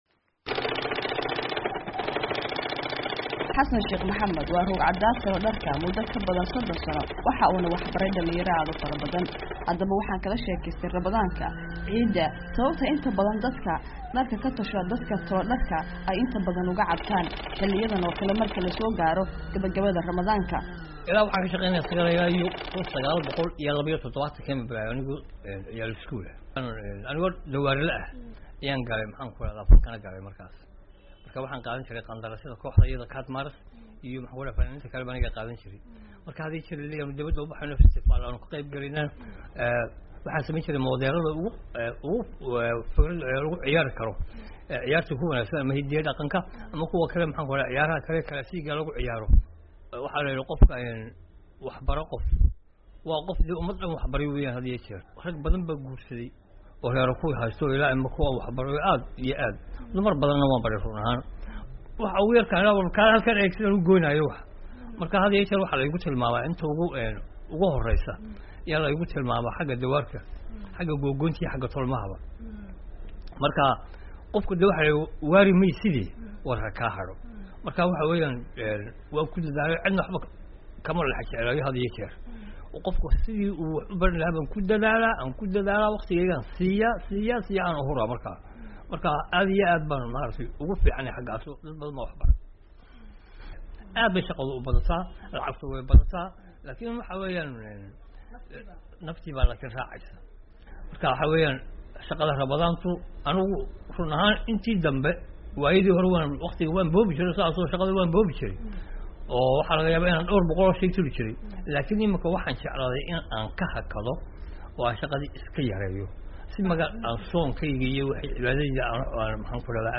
Warbixinta Djibouti